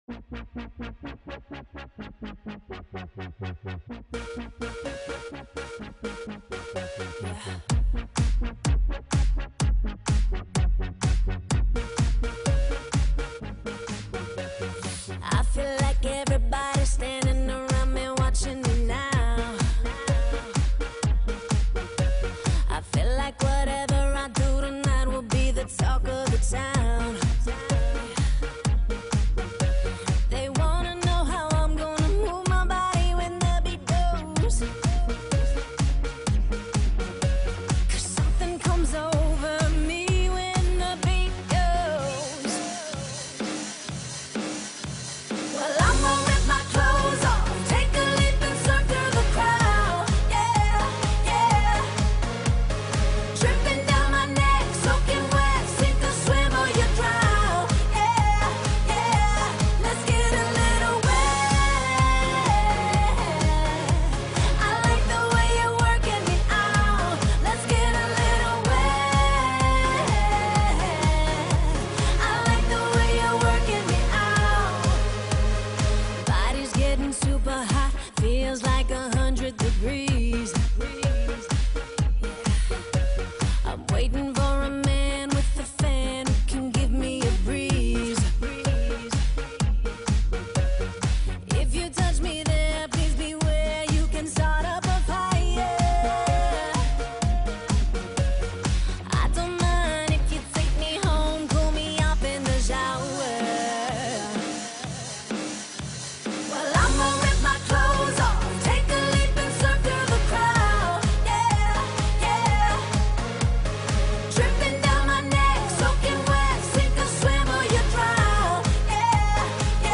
Pop, Dance